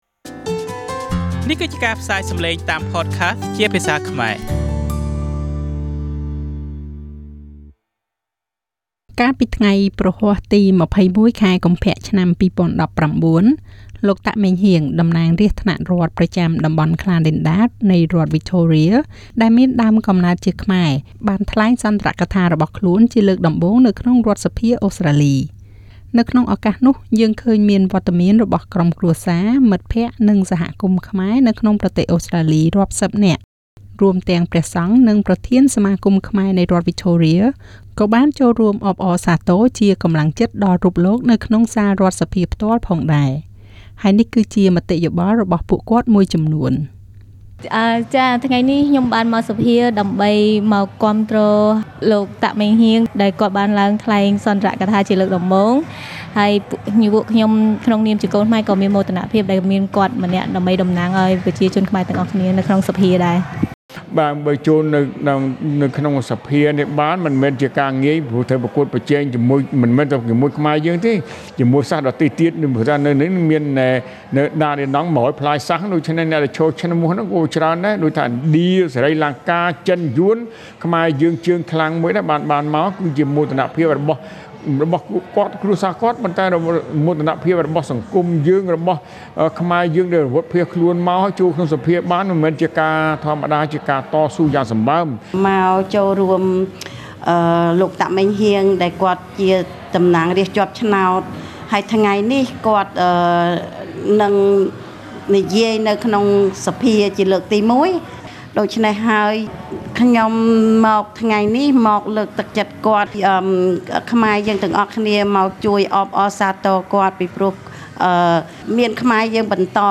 Victorian MP Meng Heag Tak speech at the parliament. Source: Parliament of Victoria